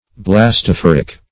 Search Result for " blastophoric" : The Collaborative International Dictionary of English v.0.48: Blastophoral \Blas`toph"o*ral\, Blastophoric \Blas`to*phor"ic\, a. Relating to the blastophore.